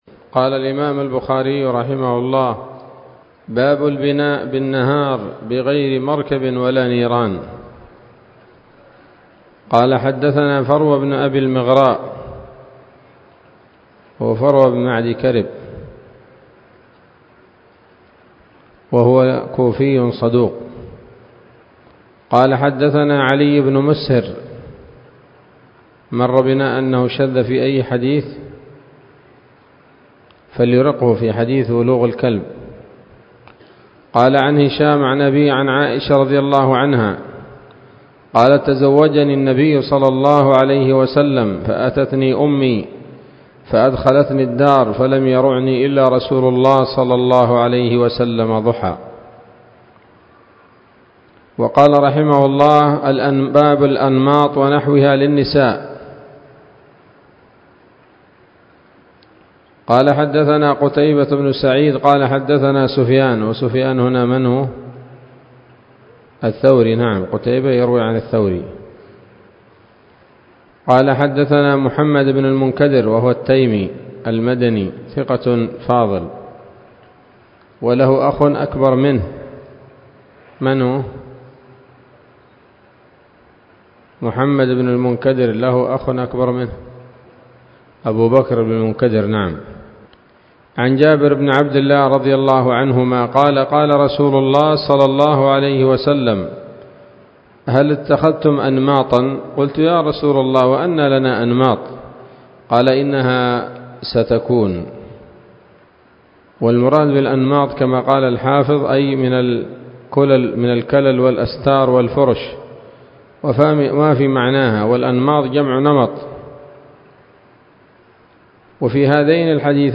الدرس الثالث والخمسون من كتاب النكاح من صحيح الإمام البخاري